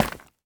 Minecraft Version Minecraft Version latest Latest Release | Latest Snapshot latest / assets / minecraft / sounds / block / tuff_bricks / step1.ogg Compare With Compare With Latest Release | Latest Snapshot
step1.ogg